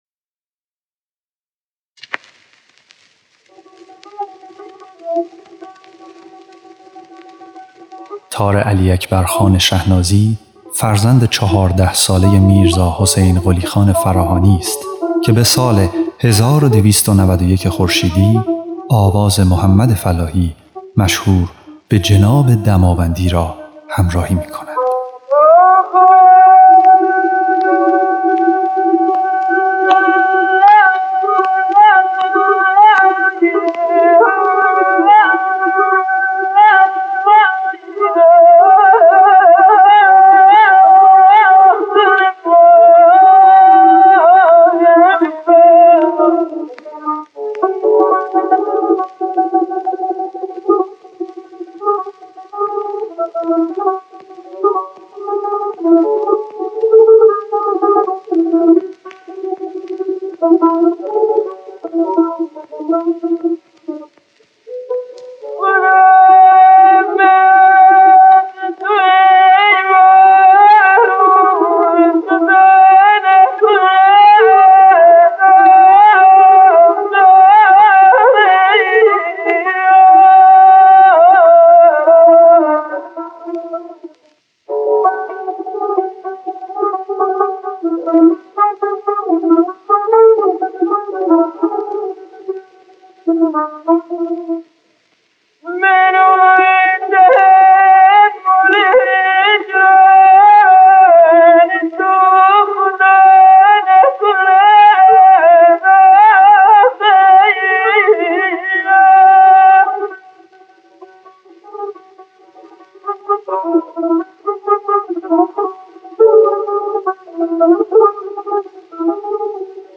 ضبط سری اول آثار
خواننده
نوازنده تار